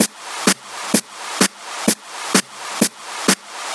VEH1 Fx Loops 128 BPM
VEH1 FX Loop - 35.wav